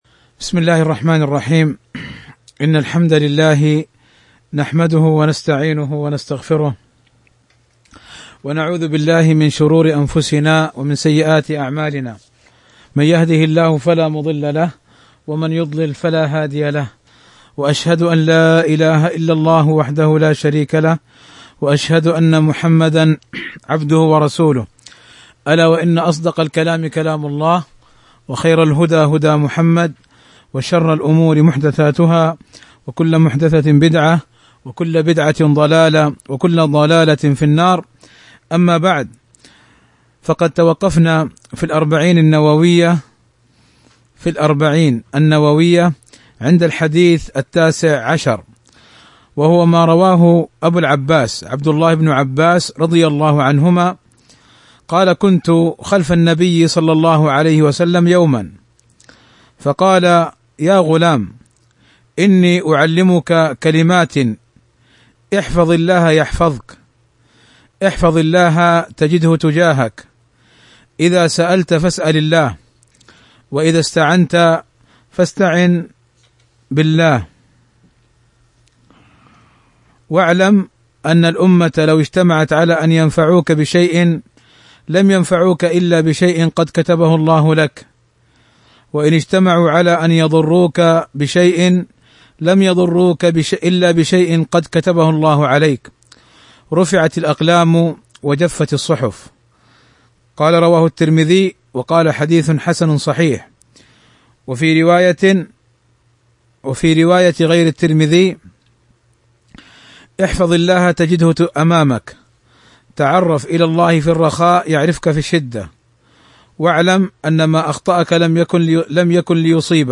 شرح الأربعون النووية الدرس 17